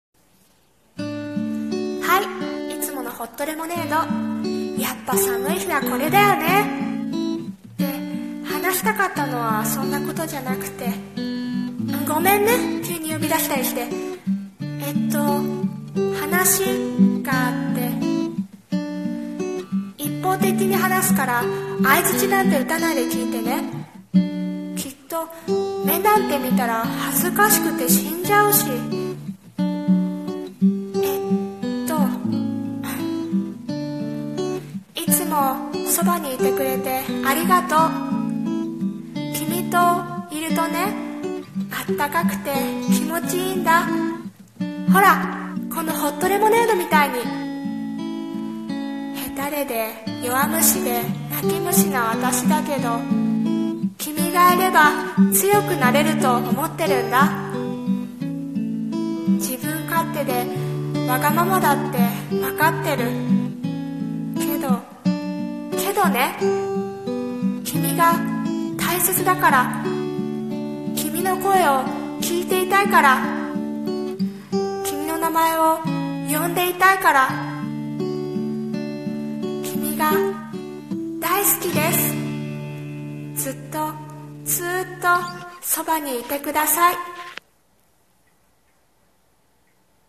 さんの投稿した曲一覧 を表示 声劇 ホットレモン